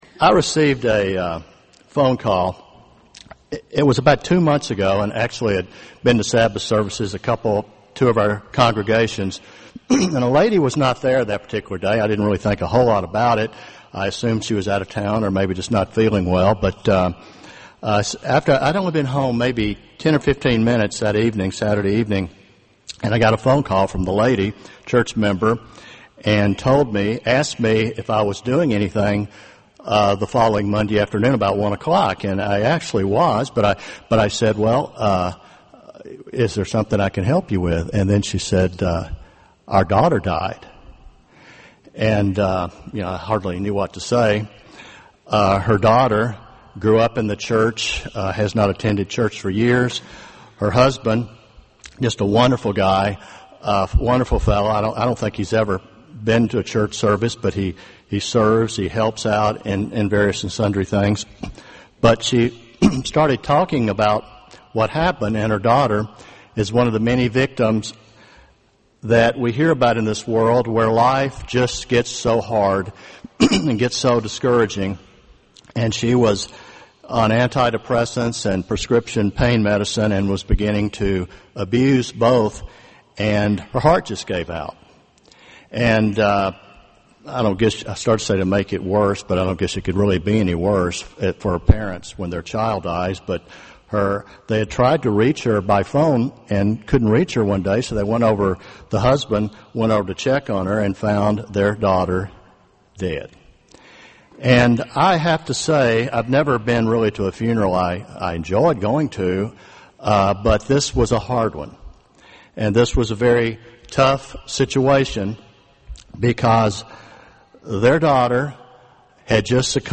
This sermon was given at the Jekyll Island, Georgia 2013 Feast site.